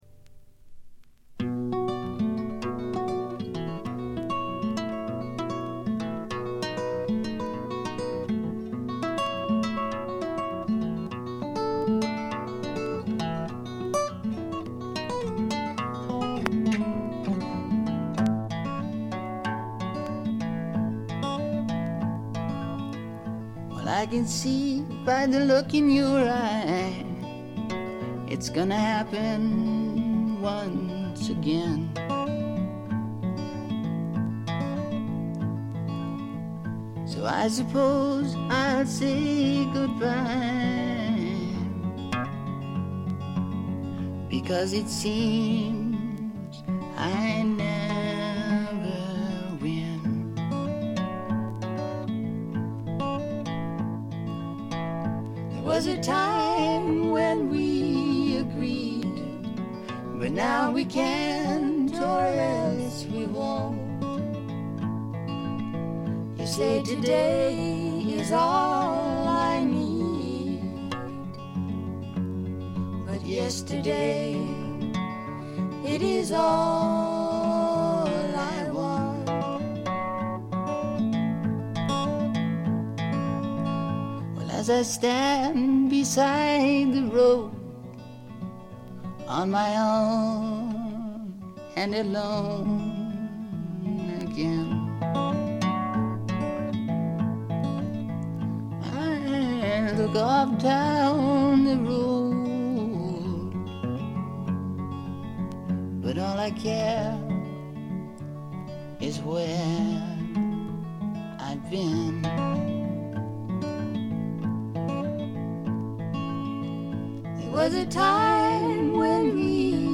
B2イントロでプツ音2回。
スワンプ路線とフォーキー路線が半々でどちらも素晴らしい出来ばえです。
試聴曲は現品からの取り込み音源です。
Vocals, Acoustic Guitar